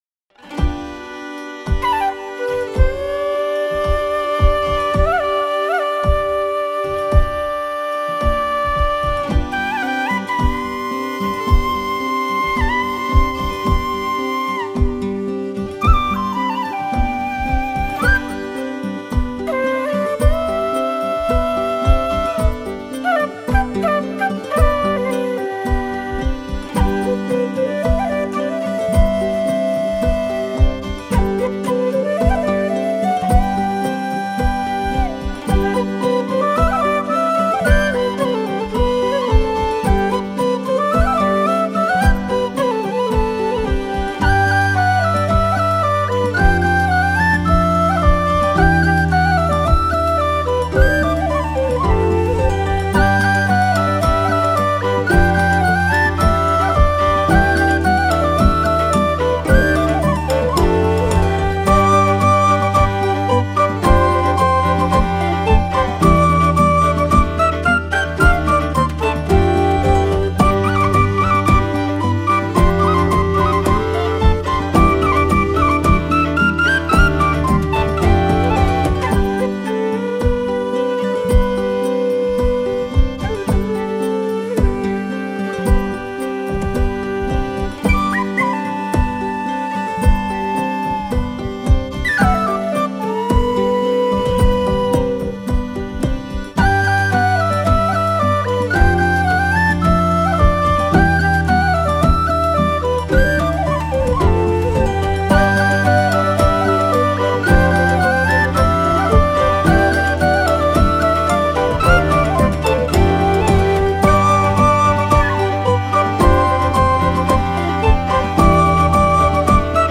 Genre:World
このパックには木管楽器と打楽器しか含まれていませんのでご注意ください。
183 Bamboo Flute Loops
144 Percussion Loops